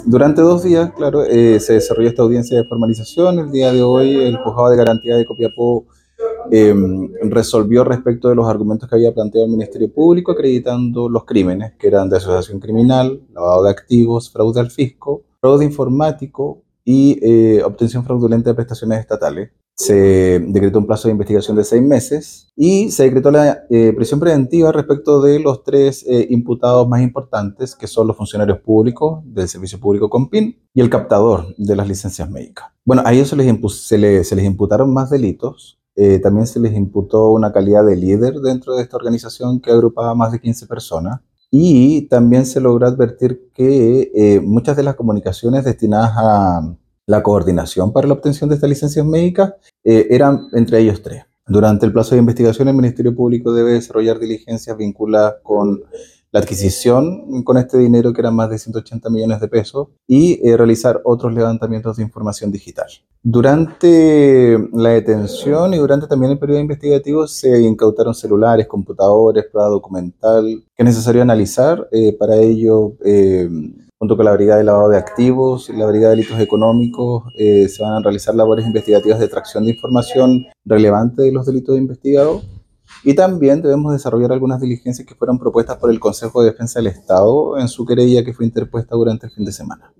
AUDIO-FISCAL-LUIS-MIRANDA-FLORES.mp3